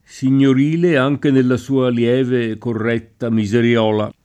miseriola [ mi @ er L0 la ]